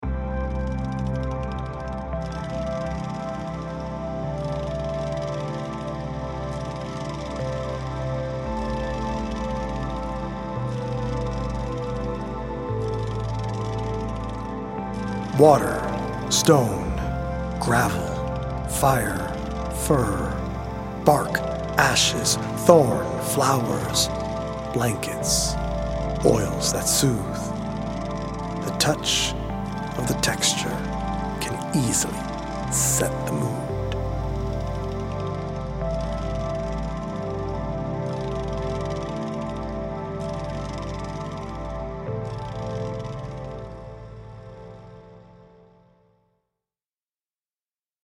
audio-visual poetic journey
healing Solfeggio frequency music